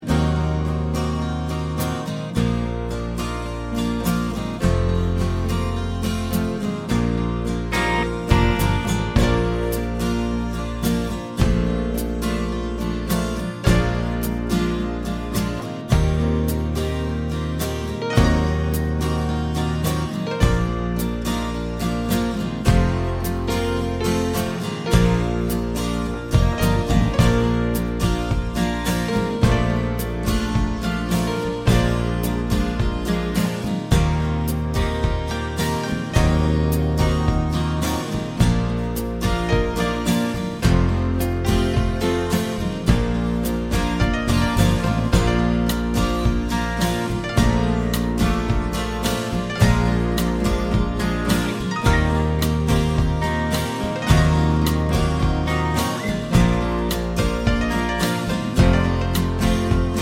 Instrumental Cut Down Pop